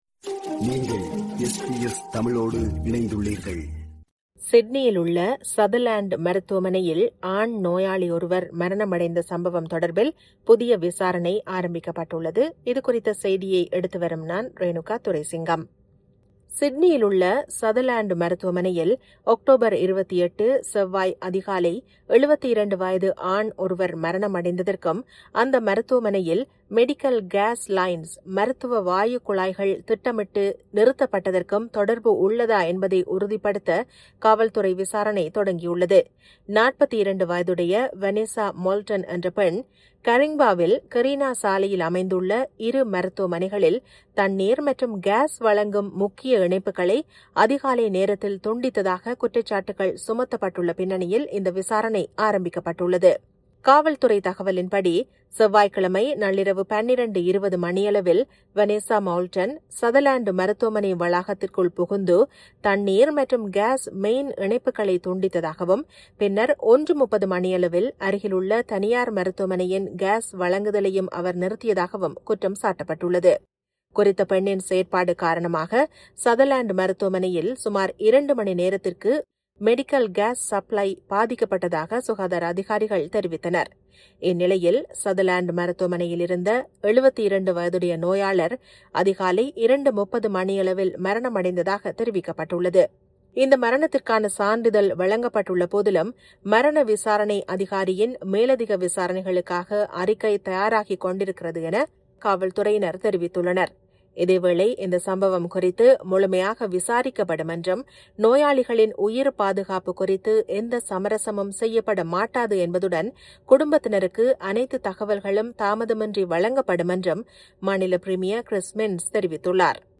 சிட்னியில் உள்ள Sutherland மருத்துவமனையில் ஆண் நோயாளி மரணமடைந்த சம்பவம் தொடர்பில் புதிய விசாரணை ஆரம்பிக்கப்படுகிறது. இதுகுறித்த செய்தியை எடுத்துவருகிறார்